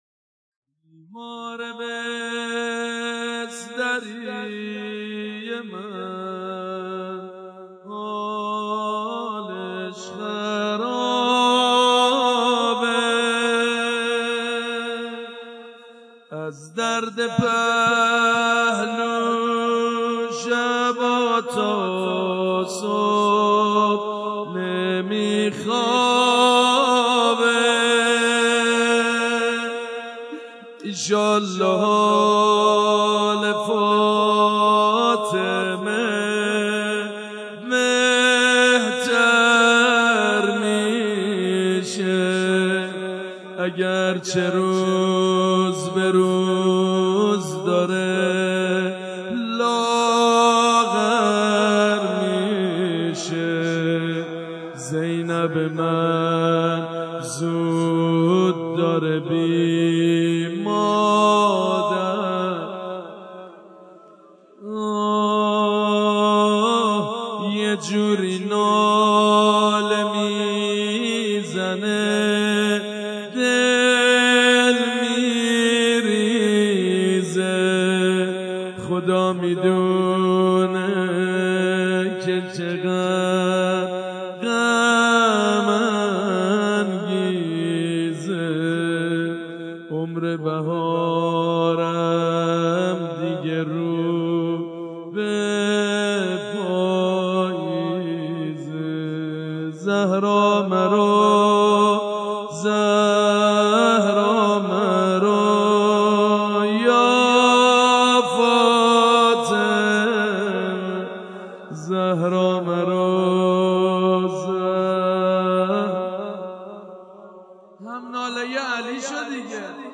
ذکر مصیبت شهادت بانوی دوعالم حضرت زهرا(س